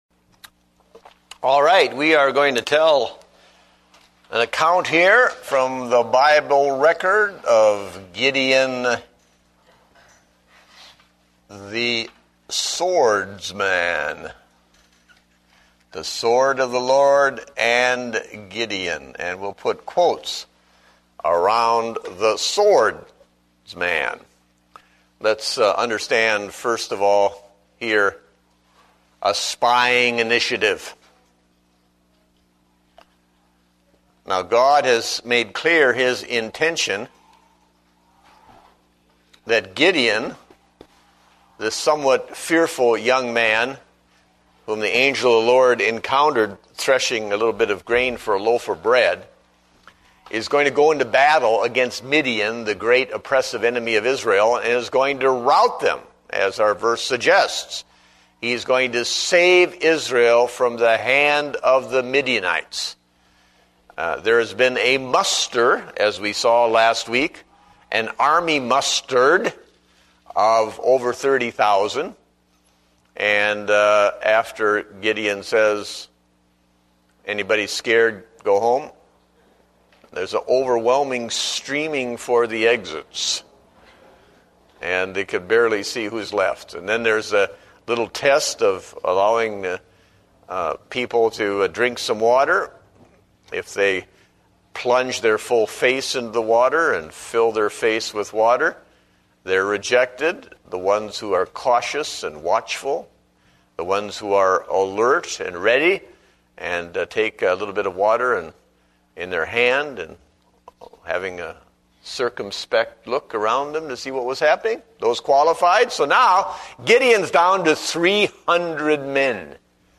Date: August 30, 2009 (Adult Sunday School)